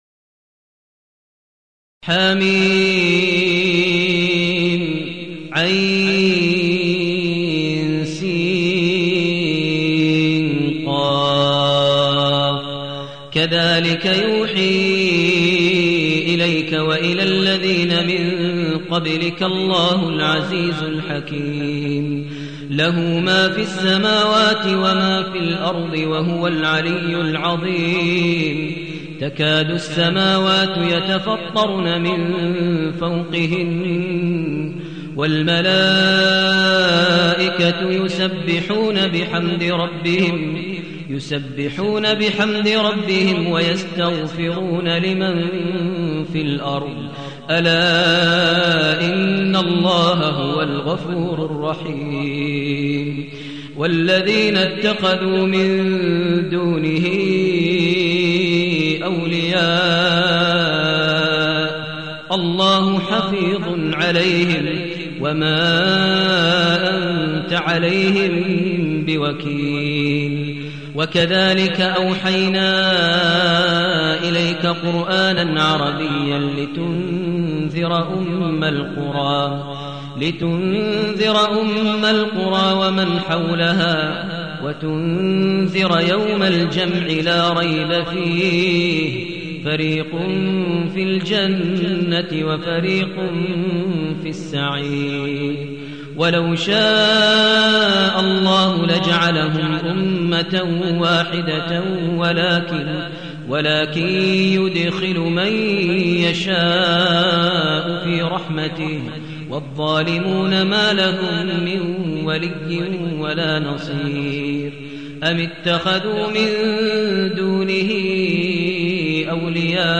المكان: المسجد الحرام الشيخ: فضيلة الشيخ ماهر المعيقلي فضيلة الشيخ ماهر المعيقلي الشورى